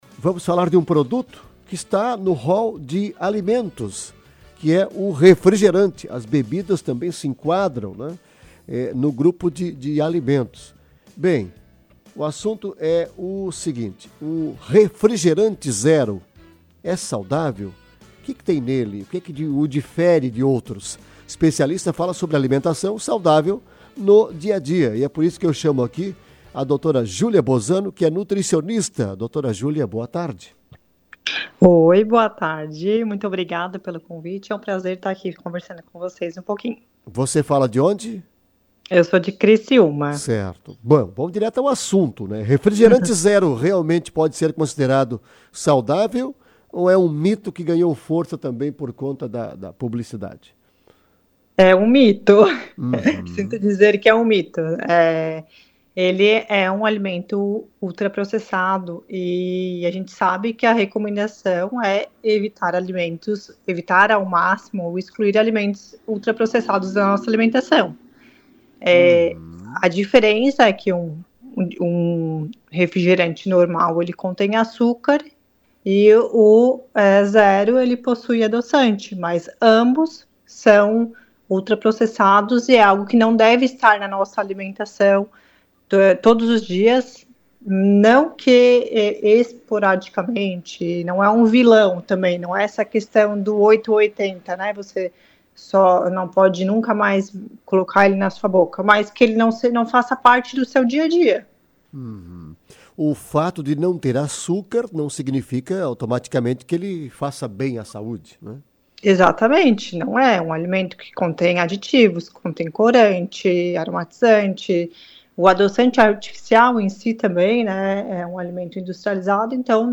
Nutricionista destacou mais sobre o tema em entrevista ao Ponto de Encontro